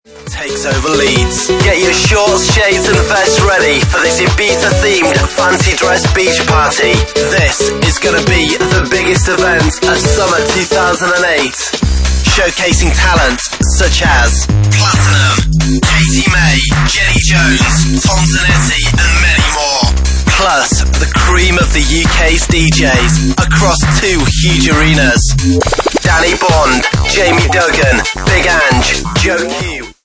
Bassline House at 136 bpm